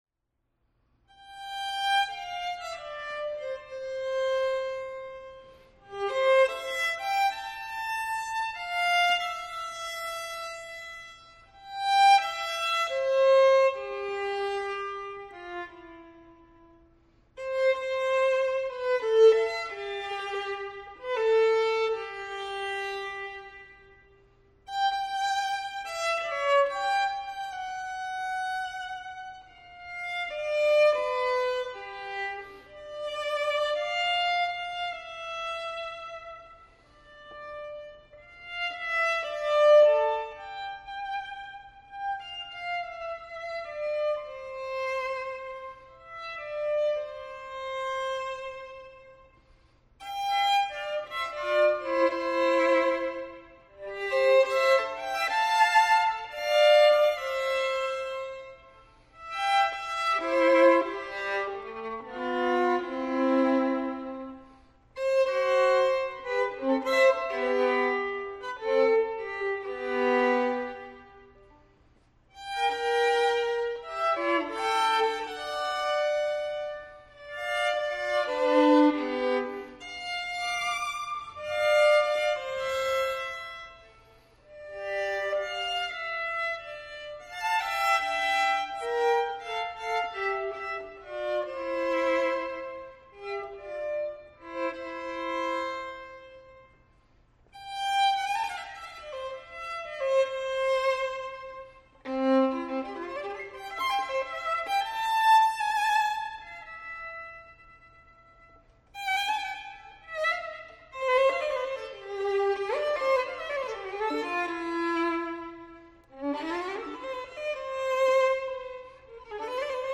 Library of Congress Washington (Coolidge Auditorium)
for solo violin reaches back to Orpheus